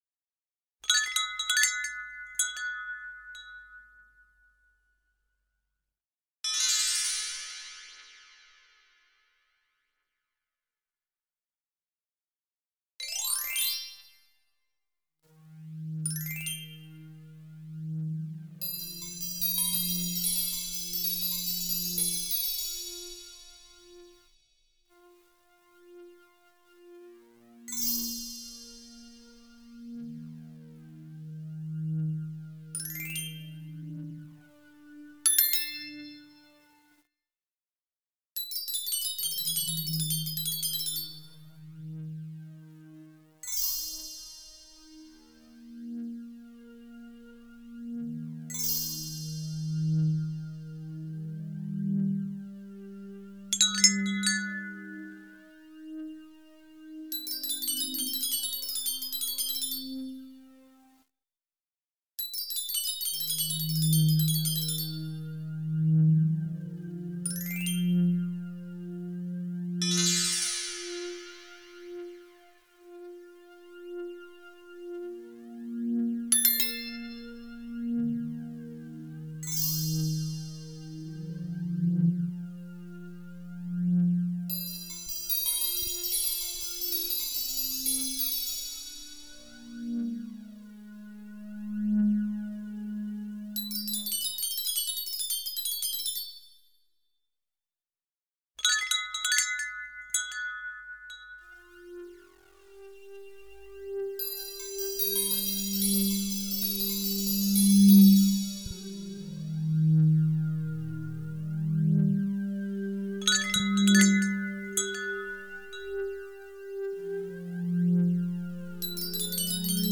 256SOUND consists out of a visual and AI generated auditory artwork, derived from the data in the genesis 256 ART pieces.